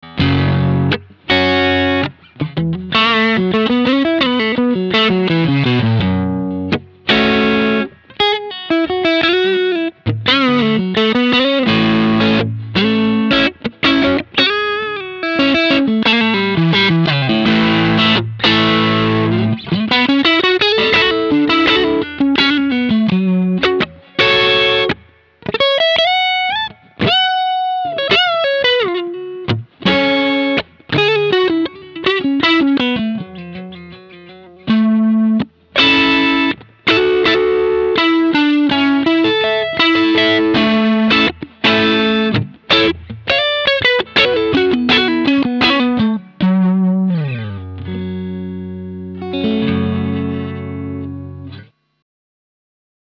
Es legt sich lediglich eine weiche Verzerrung über den Gitarrensound.
Nobels ODR-1 BC Soundbeispiele
Das Nobels ODR-1 BC bietet einen tollen, cremigen Overdrive Sound, der die Grundcharakteristik des Verstärkers kaum färbt.